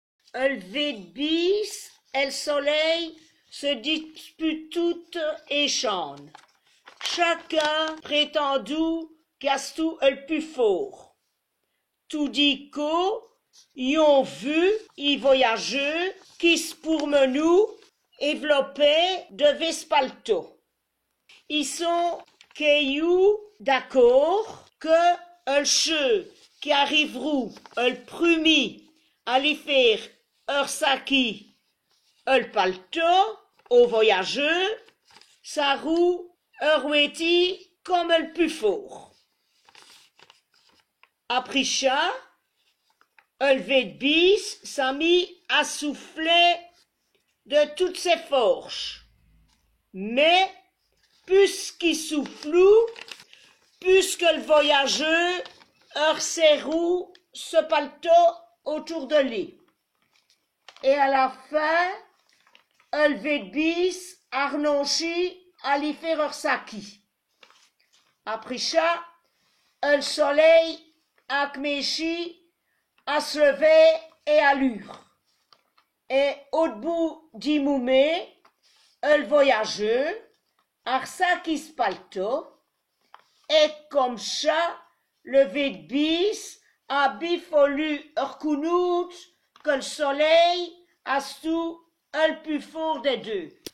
- Picård d' Ate
C' est l' picård.
Ciddé, vos ploz schoûter èn eredjistrumint del fåve do vî vî tins «Li bijhe et l' solea» ratournêye avou l' accint did par la pol Djåzant atlasse éndjolike des lingaedjes di France et d' avår la.